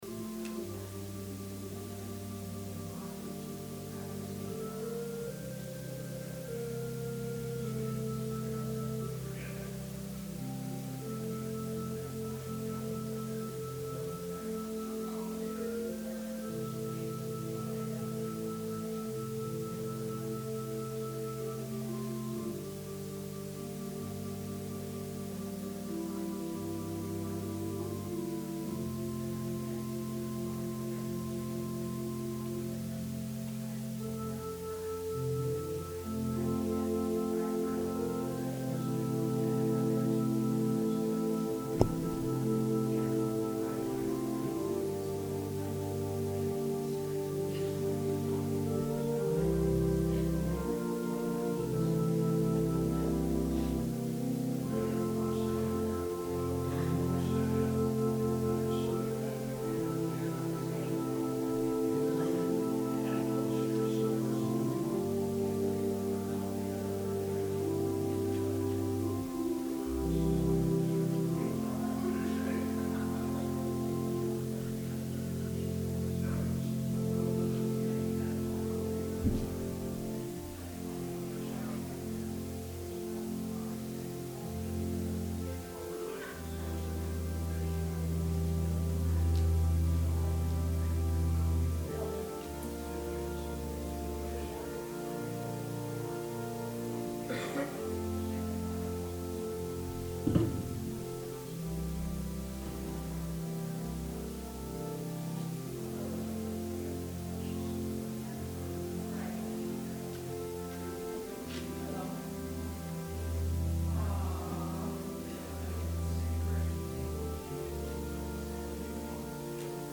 Sermon – February 16, 2020